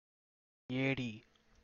Captions English Kannada pronunciation of "eedi"